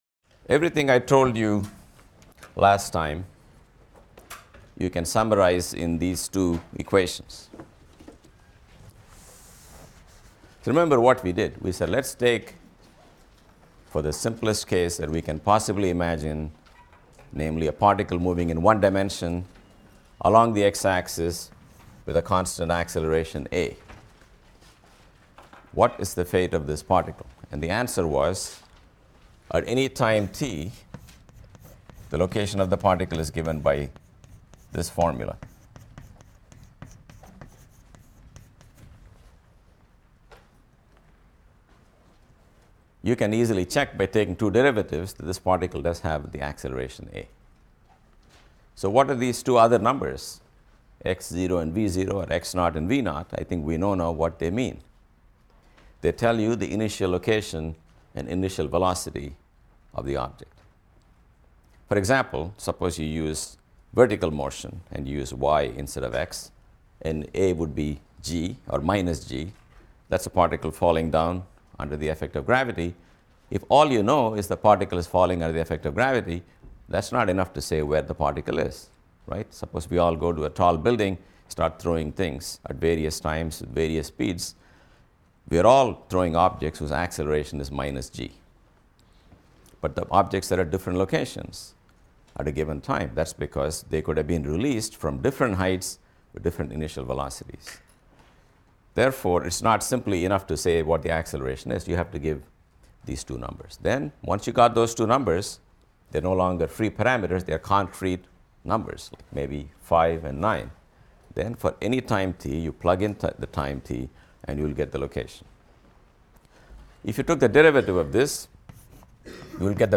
PHYS 200 - Lecture 2 - Vectors in Multiple Dimensions | Open Yale Courses